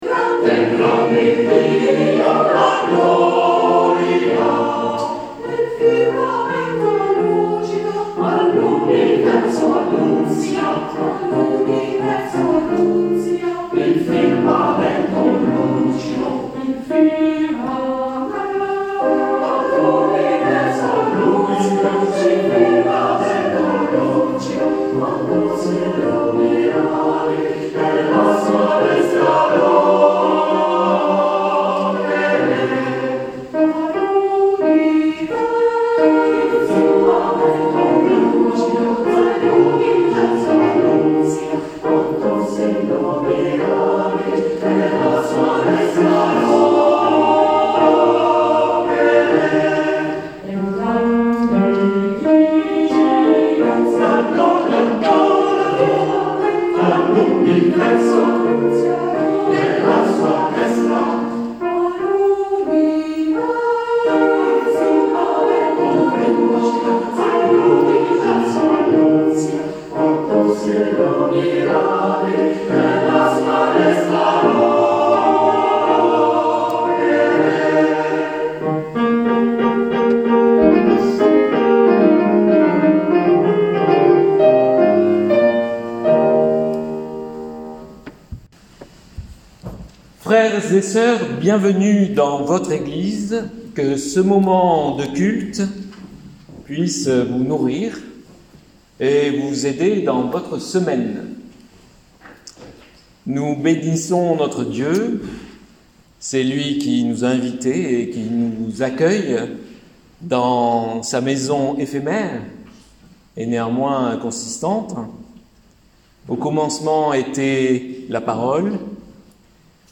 AUDIO DU CULTE DU 12 DÉCEMBRE 2021
Accompagné par la pianiste